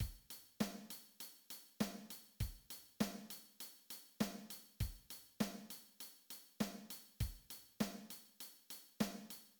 Backbeat_example.mid.mp3